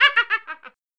laugh1.wav